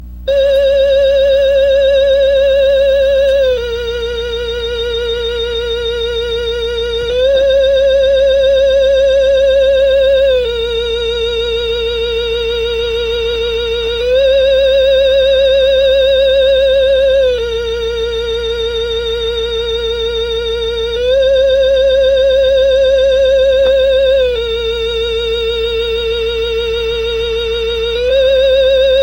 Halloween Decoration Sound Soundboard: Play Instant Sound Effect Button